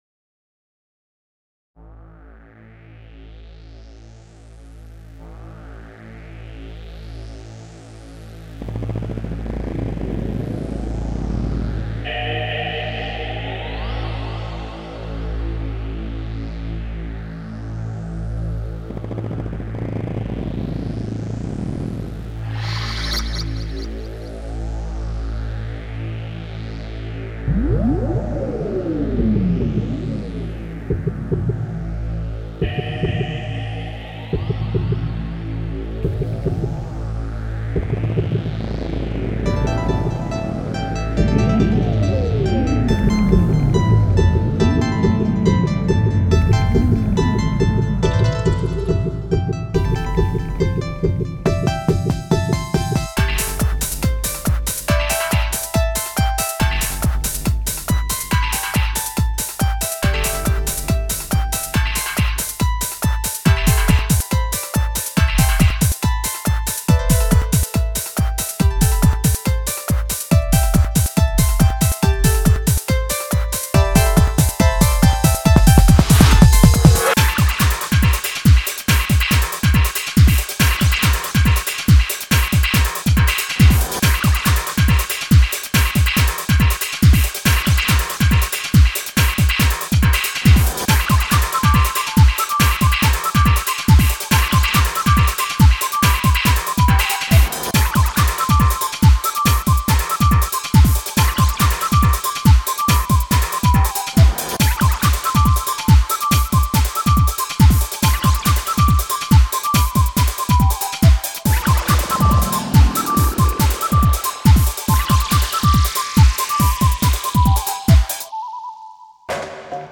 2006 Электронная